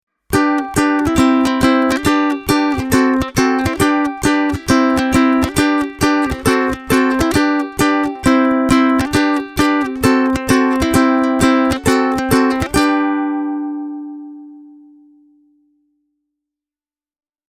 Valitettavasti testiyksilön tallamikrofonin asennuksessa (tai sen toiminnassa) oli jotain häikkää, minkä takia ukulelen kaksi reunimmaiset kielet eivät kuulu käytännössä lainkaan: